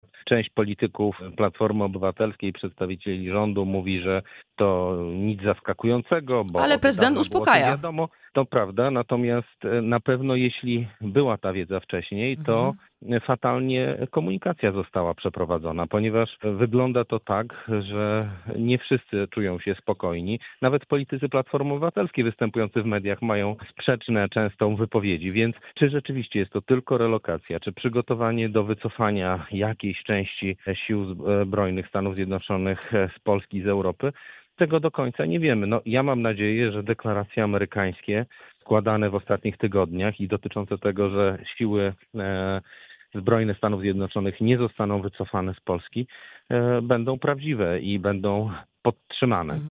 Michał Dworczyk był gościem wrocławskiego radia „Rodzina”